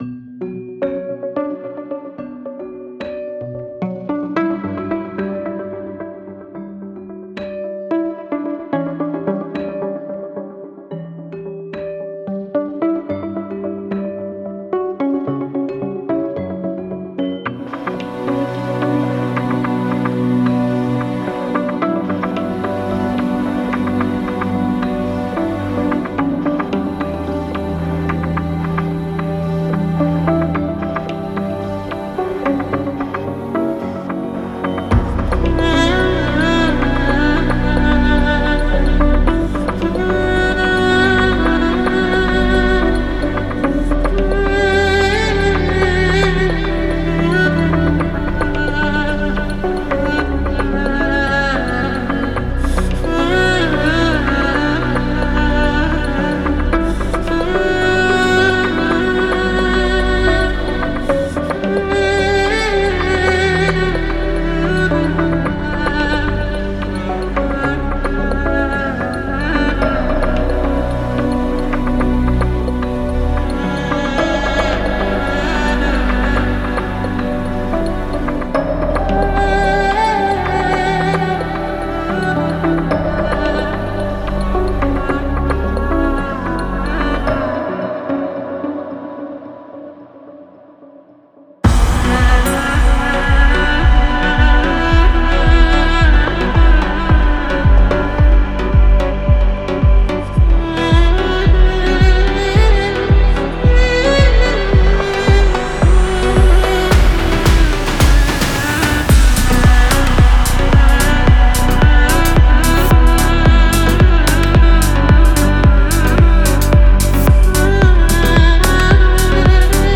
атмосферная электронная композиция